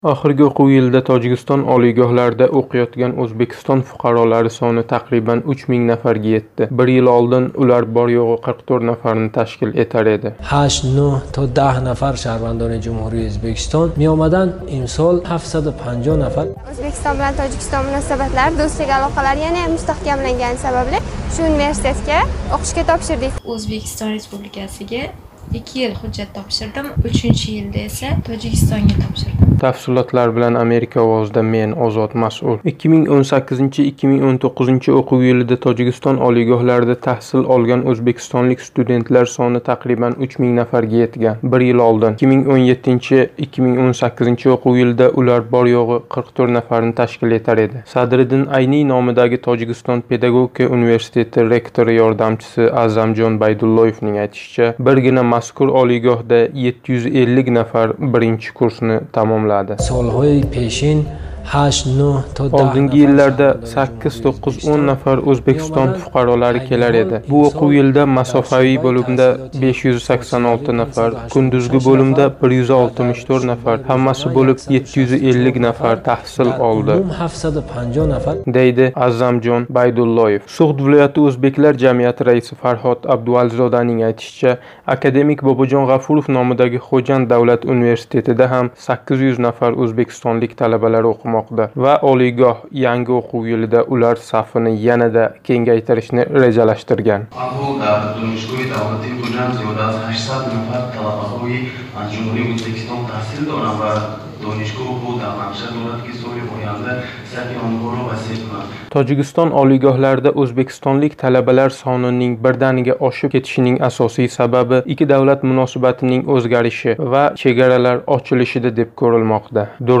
Tojikistonda tahsil olayotgan o'zbekistonlik talaba bilan suhbat